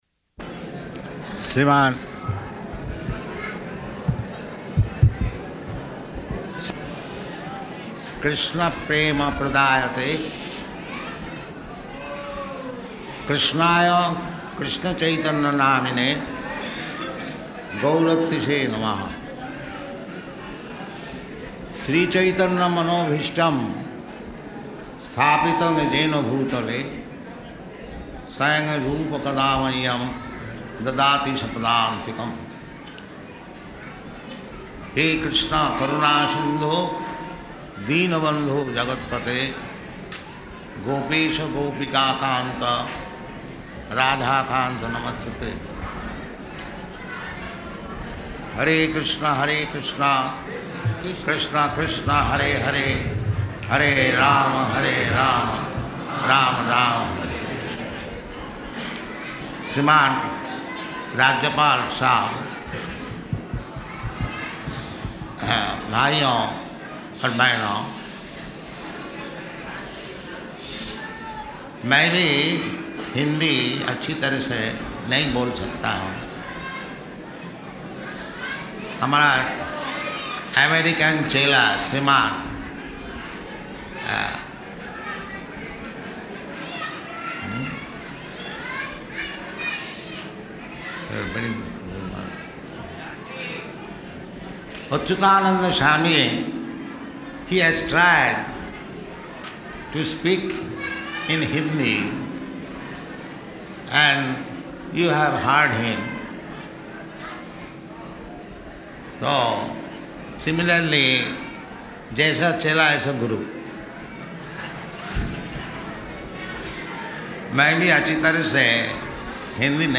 Speech, Śrī Caitanya-caritāmṛta, Madhya 19.53
Speech, Śrī Caitanya-caritāmṛta, Madhya 19.53 --:-- --:-- Type: Lectures and Addresses Dated: April 20th 1975 Location: Vṛndāvana Audio file: 750420SP.VRN.mp3 Prabhupāda: Śrīman...